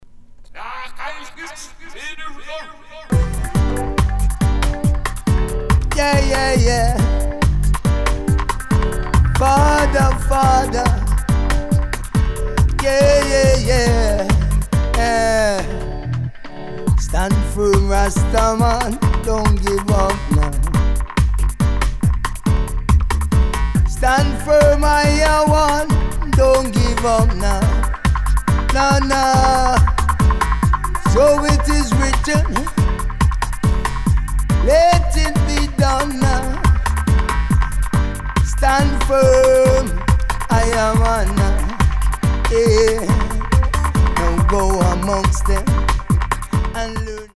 Recorded: Oxford, U.K.